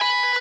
guitar_008.ogg